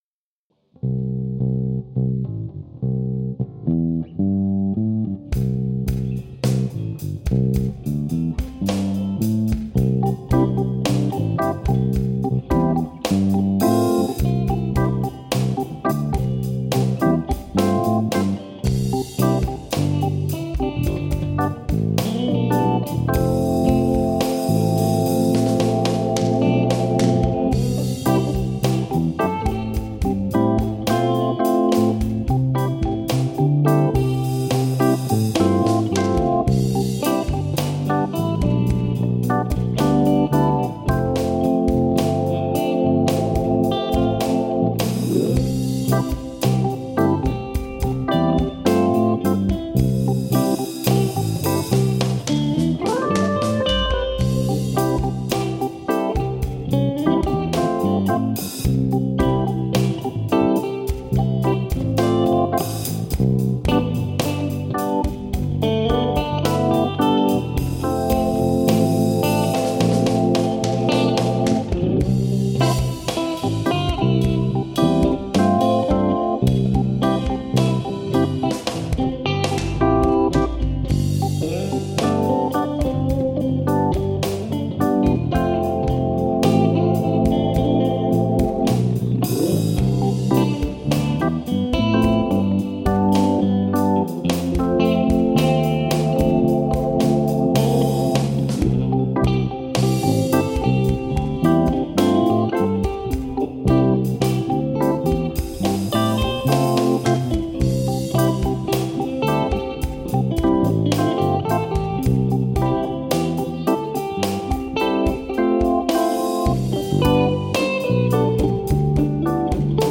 organ, bass, drums
guitars
This jam was a rough recording done in a single session.
The guitars were recorded with a close-mic to the amplifier.
these aren’t very audible on the recording but represent some possible melody ideas
They will be a little rough around the edges, and unlike the polished songs we spend weeks and weeks creating.
• Instruments: Fender Stratocaster, Peavy Fury bass
• Microphone: Shure SM-57